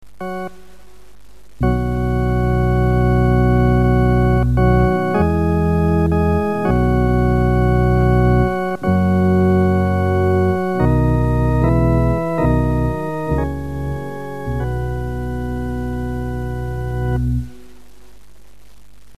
Masonic Music for Lodge and Chapter
Organ.